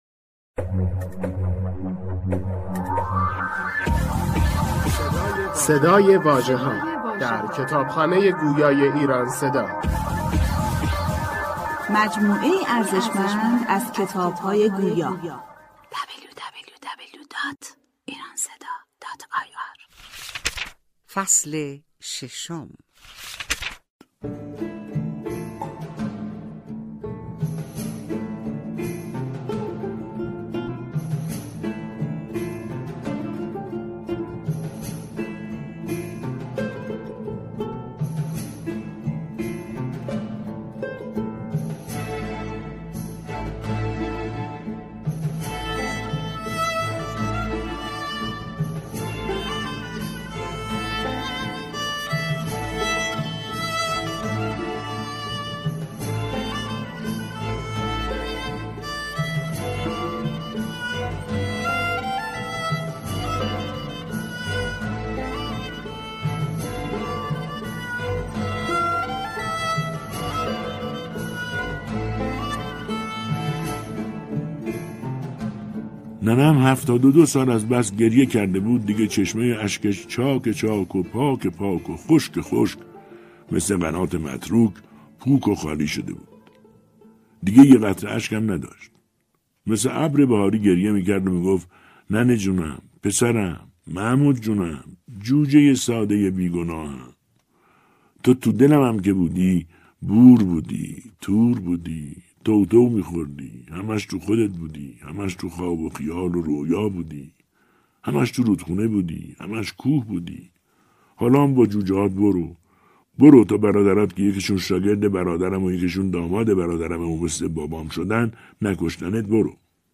کتاب صوتی ده سال هوملسی امریکا نوشته سید محمود گلابدره ای فصل ششم